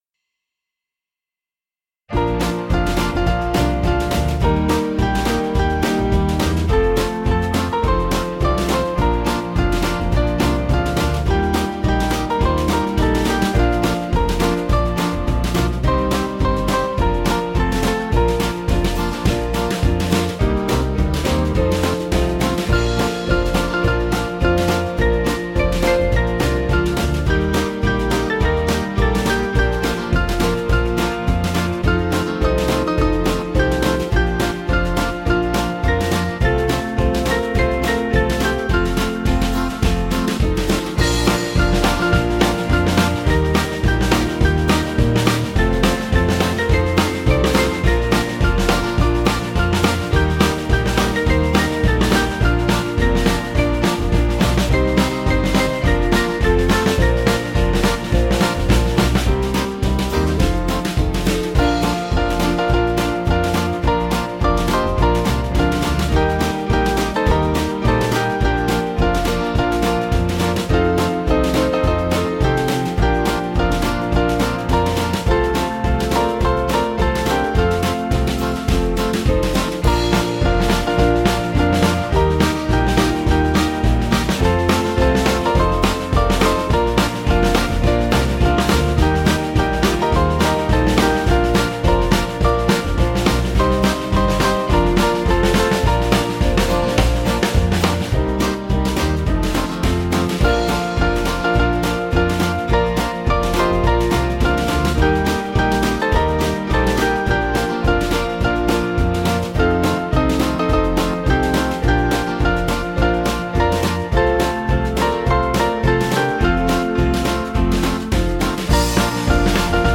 Small Band
(CM)   3/Bb 474kb